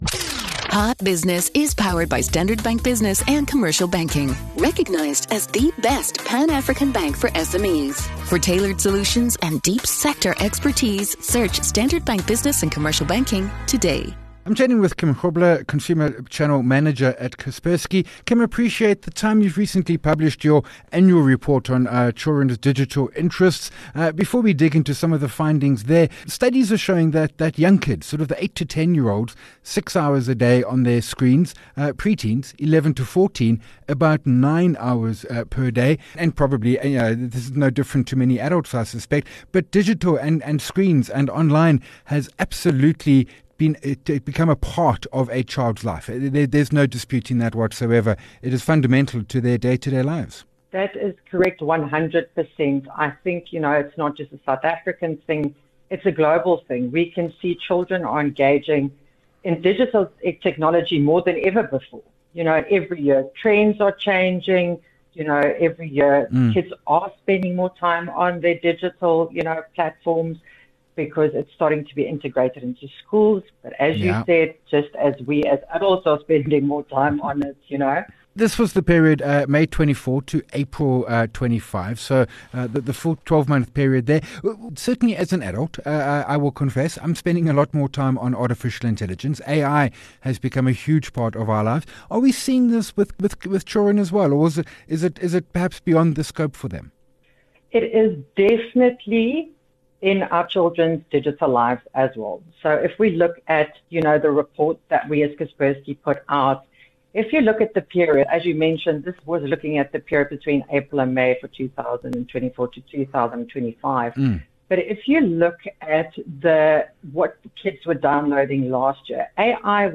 INDUSTRY SPOTLIGHT Topic: Kaspersky: AI curiosity among children more than doubled in 2025 Guest